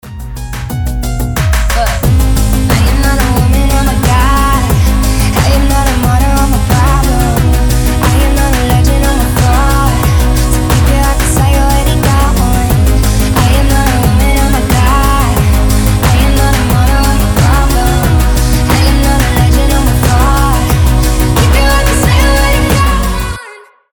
• Качество: 320, Stereo
женский голос
Electronic
alternative
поп-панк